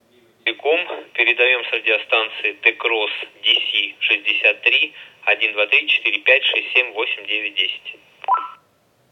Портативная безлицензионная радиостанция диапазона PMR 446 МГц.
Пример модуляции (передачи) радиостанции Decross DC63: